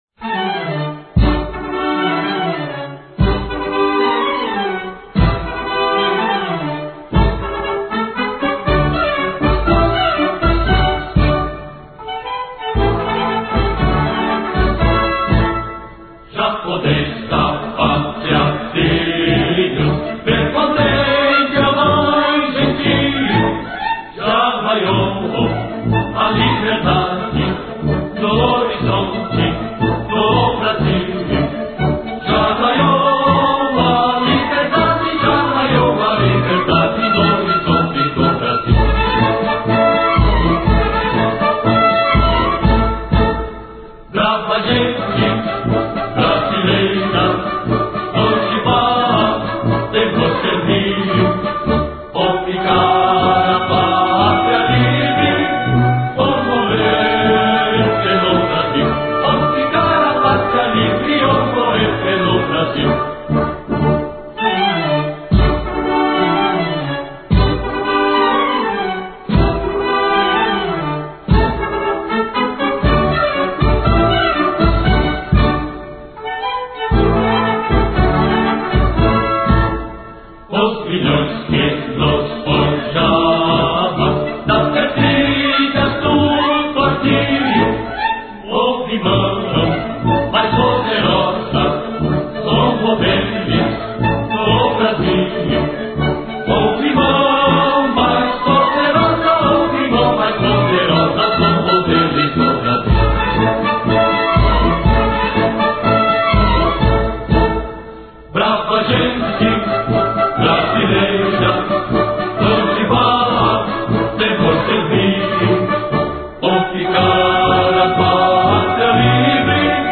Hino da Independência, com a Banda da Polícia Militar do Estado de São Paulo